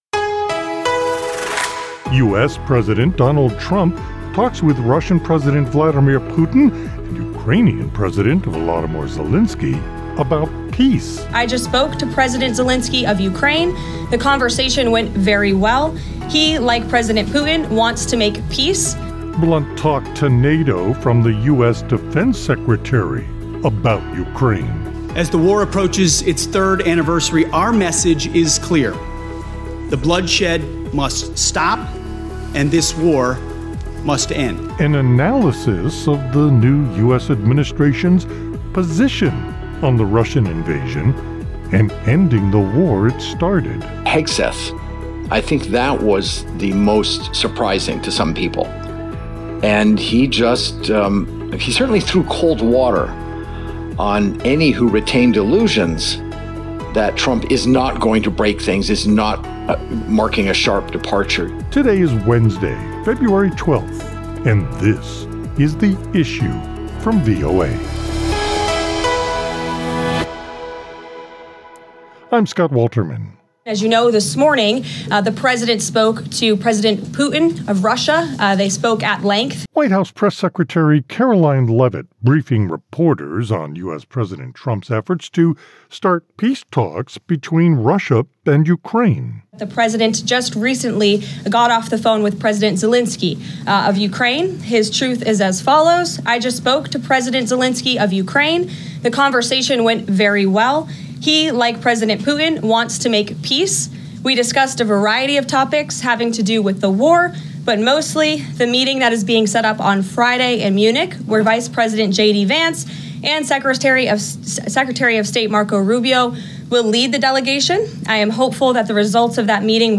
A live broadcast from Los Angeles at the 49th Annual Grammy Pre-Tel Awards. The “pre-television” awards ceremony was never broadcast before this.